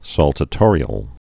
(săltə-tôrē-əl, sôl-)